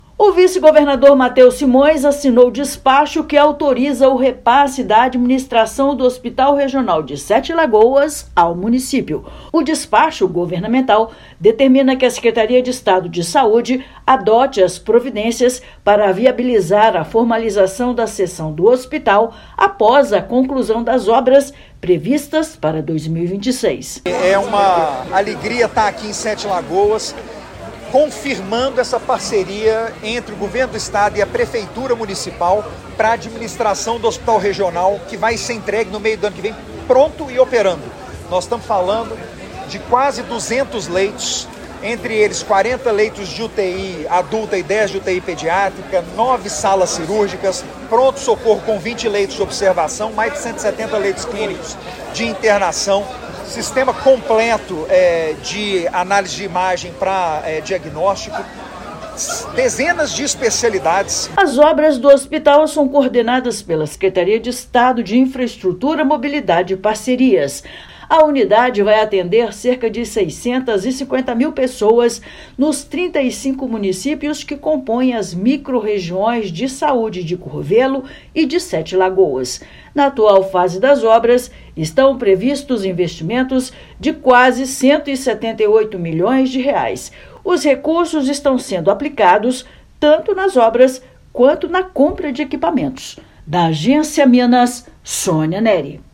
Unidade hospitalar deve começar a funcionar em 2026 e atender cerca de 650 mil pessoas. Ouça matéria de rádio.